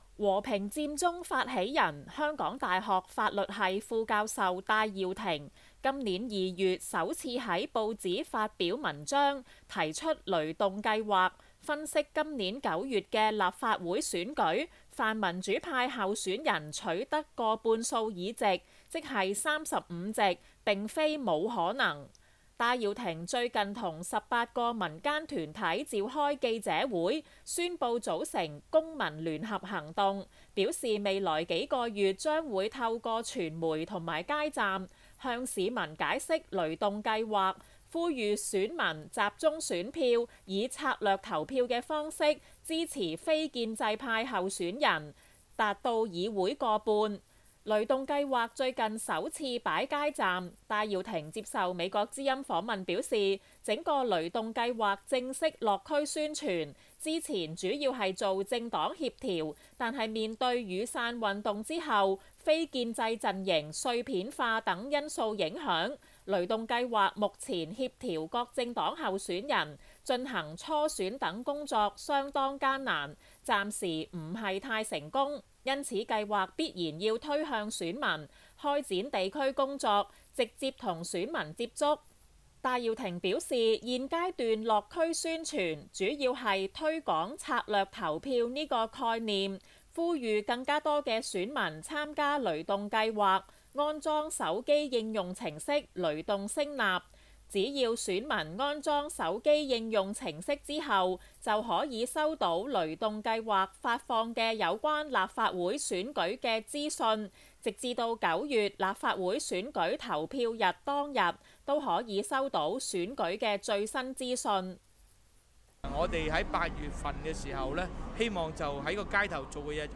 香港大學法律系副教授戴耀廷早前提出「雷動計劃」，希望在9月的立法會選舉，協調非建制派候選人取得過半議席。雷動計劃最近進行首次落區宣傳，戴耀廷接受美國之音訪問表示，推行雷動第二部份的策略投票，希望透過策略選民發揮選票的最大效用，在混戰中減低非建制陣營失利的機會。